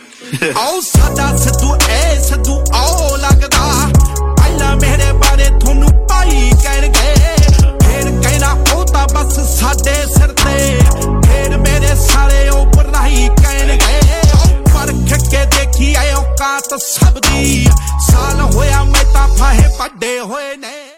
Ringtone File